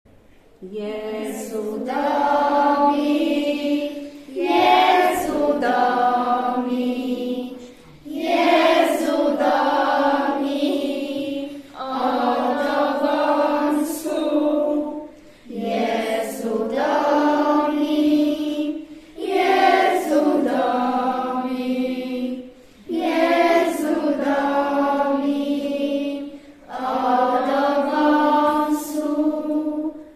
Wcześniej każda klasa przygotowała plakat o jednym z krajów z kontynentu, który jej został wyznaczony, a niektóre klasy nauczyły się śpiewać piosenki w języku w jakich mówi się na danym kontynencie czy też w kraju, o którym przygotowywały plakat.